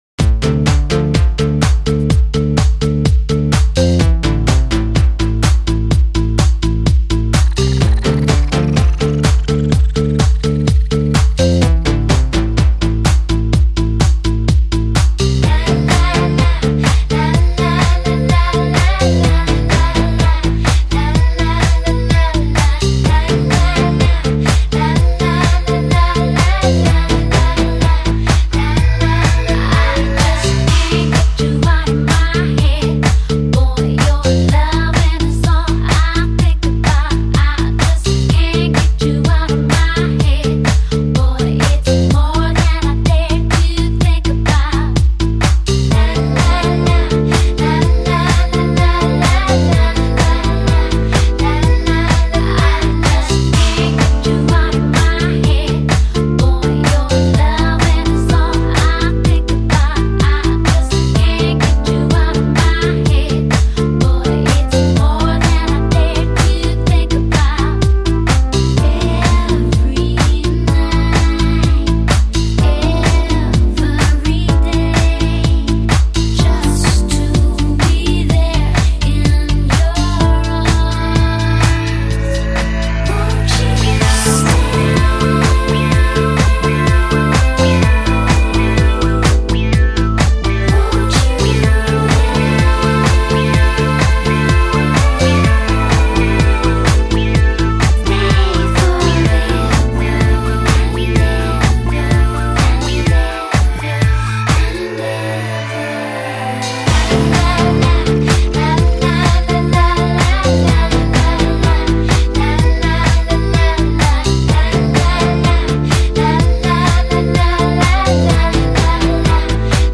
流派：Pop